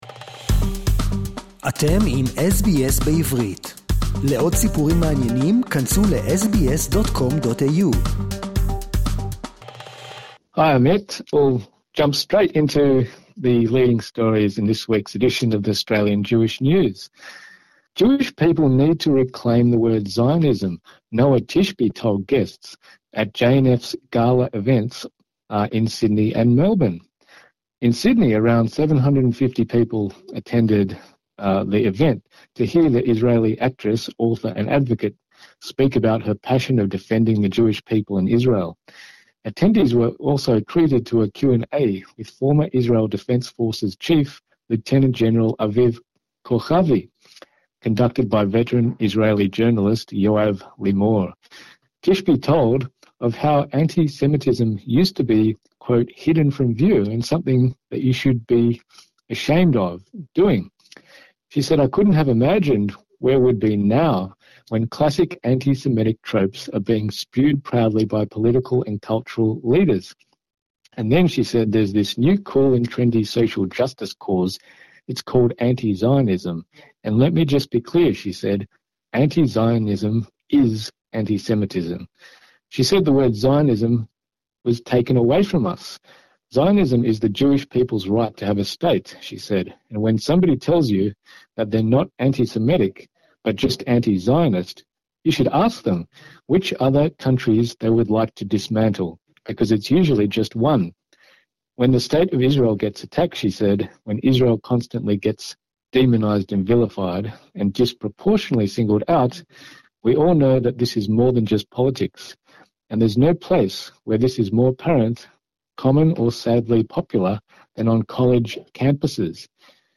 Noa Tishby, an Israeli actress, writer, and advocate speaker, was the special guest of the annual JNF Gala event in Sydney and Melbourne. Noa says that Jewish people need to reclaim the word Zionism.